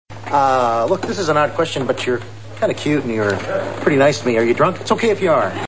Drunk girl